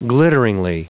Prononciation du mot glitteringly en anglais (fichier audio)
Prononciation du mot : glitteringly